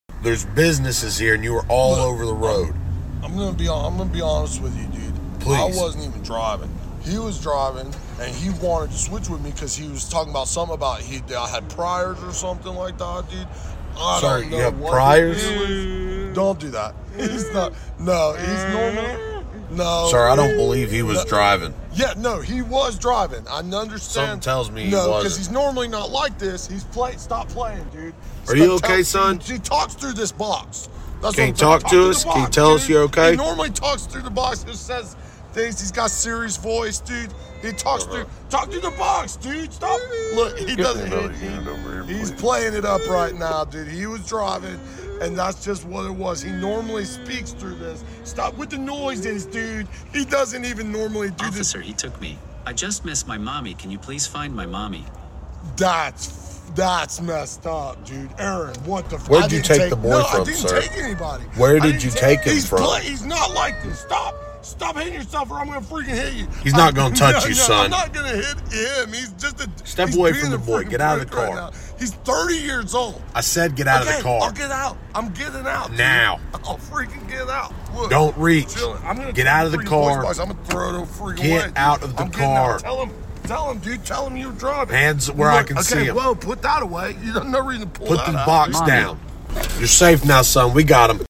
STAND-UP COMEDY